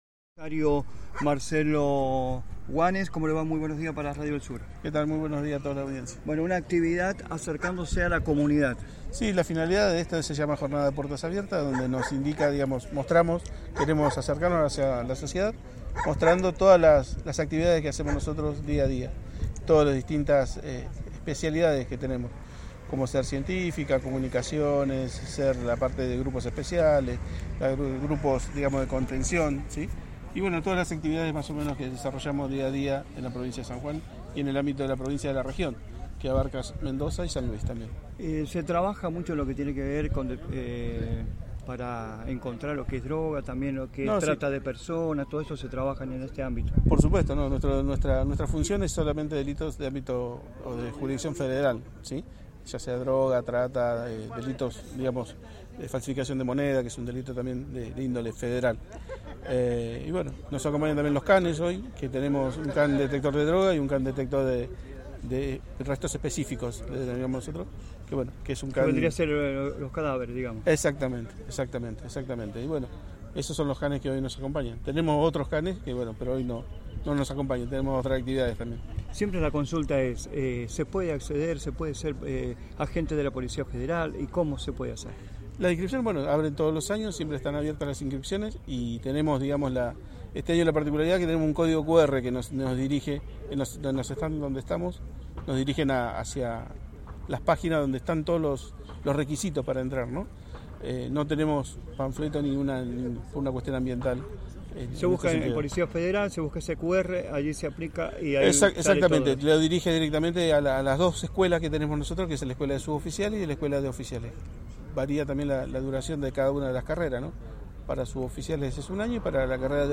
La Policía Federal realizó una jornada a puertas abiertas en el Parque de Rivadavia